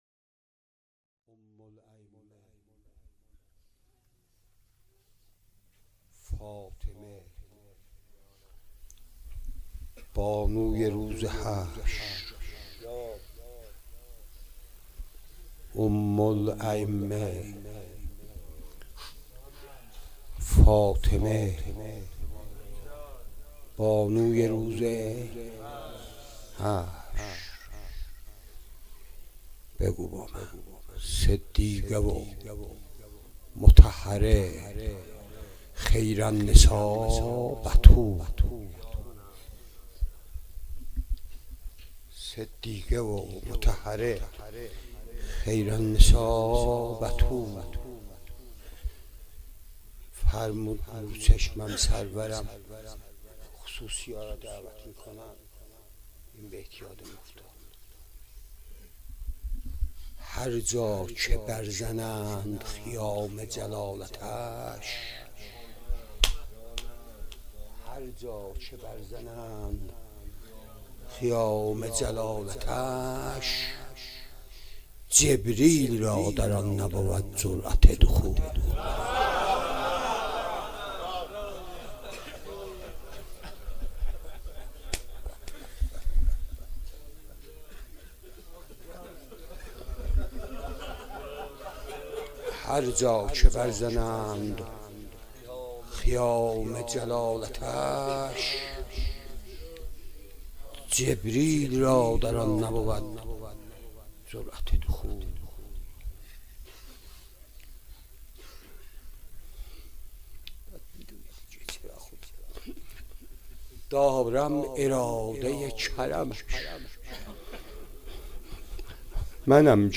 روضه | فاطمه بانوی روز حشر
مراسم سیاه پوشان عزای مادر سادات (پیشواز فاطمیه)